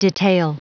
Prononciation du mot detail en anglais (fichier audio)
Prononciation du mot : detail